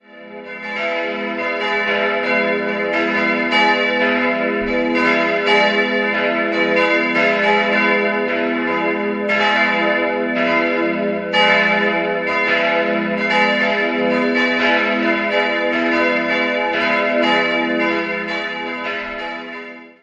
3-stimmiges E-Dur-Geläute: e'-gis'-h' Die große Glocke wurde 1892 von Stephan Hegendörfer in Amberg gegossen, die beiden kleineren Glocken stammen aus der Gießerei Petit&Edelbrock (Westfalen) und entstanden im Jahr 1950.